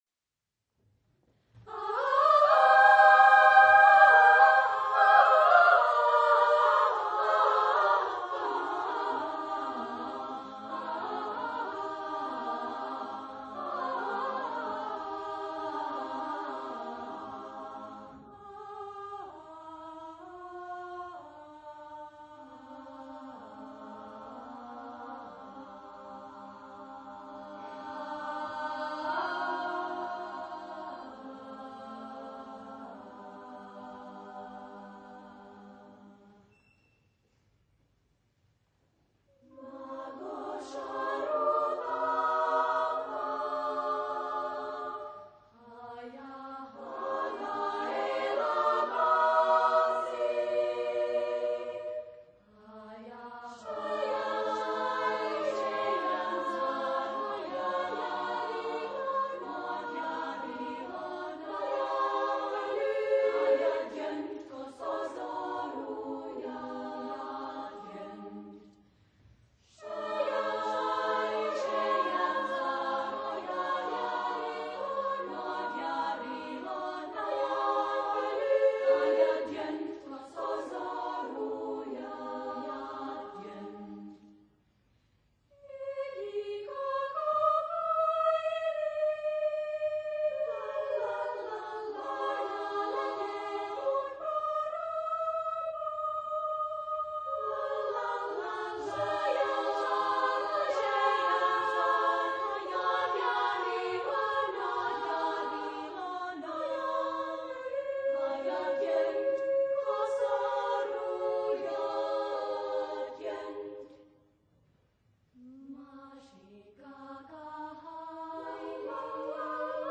Genre-Style-Forme : Chanson ; contemporain
Type de choeur : SSAA  (4 voix égales de femmes )
Tonalité : sol majeur ; do mineur ; bitonal